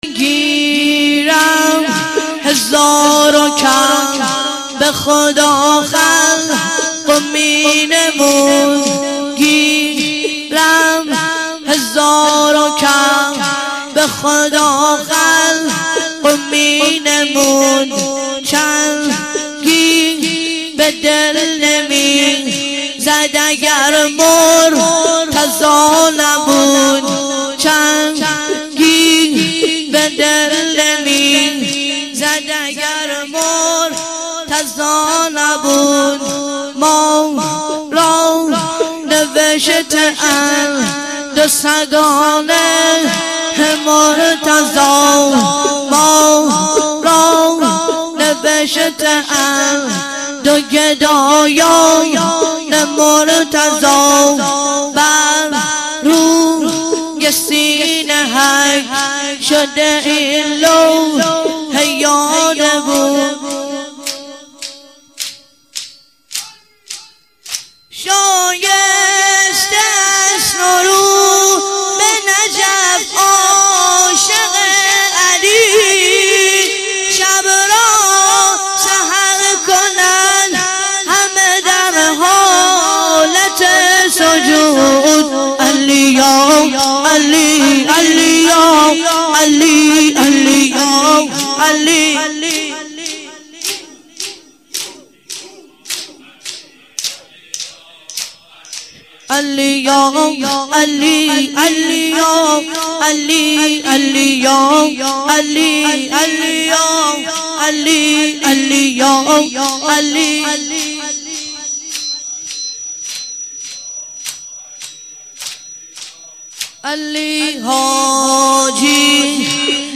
تک - گیرم هزار و کعبه خدا خلق می نمود - مداح